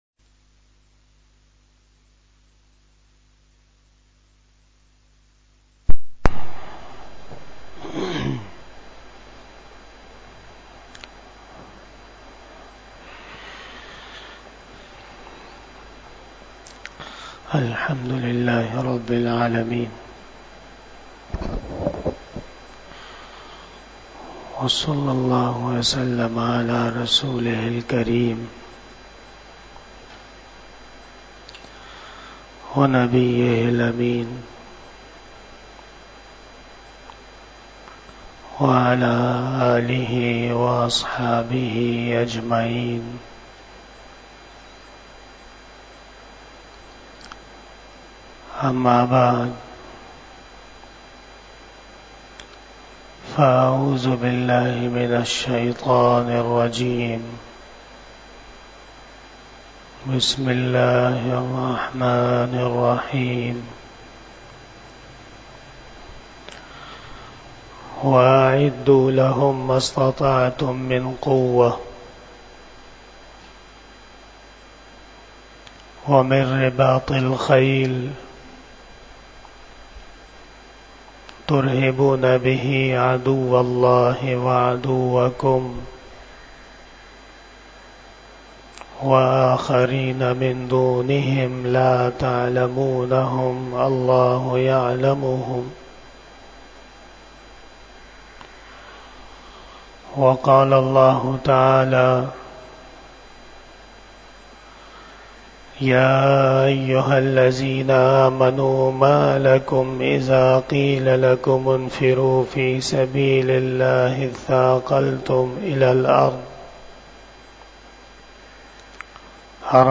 45 Bayan E Jummah 10 Nov 2023 (25 Rabi Us Sani 1445 HJ)
Khitab-e-Jummah 2023